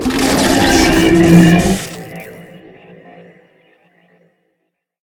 CosmicRageSounds / ogg / general / combat / enemy / droid / bighurt1.ogg
bighurt1.ogg